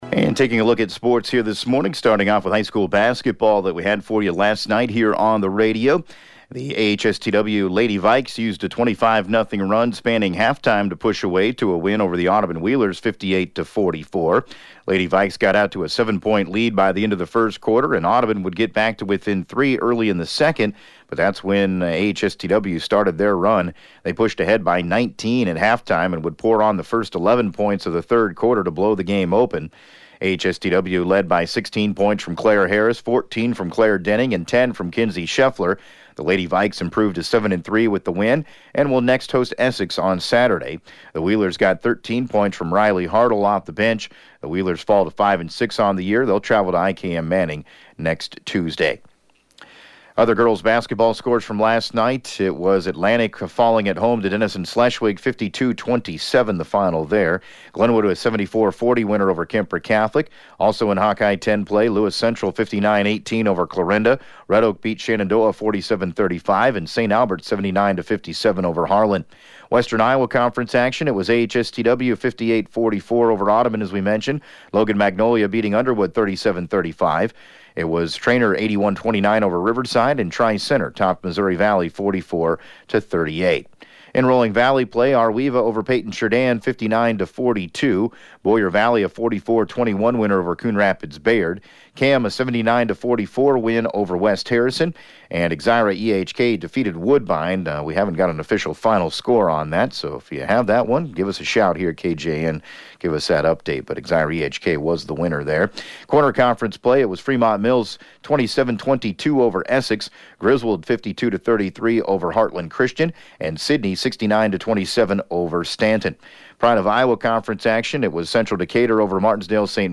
(Podcast) KJAN Morning Sports report, April 1st,2017